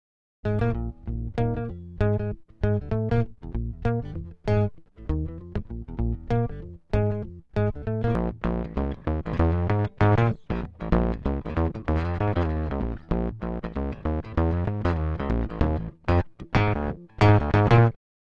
The Octave Multiplexer is finally back, generating deep, phat bass tones one octave below the notes you play into it. Dividing the Octave Multiplexer from other octave devices are the two separate smoothing filters that enable you to tailor your sub-octave signal to the exact bass sound you desire.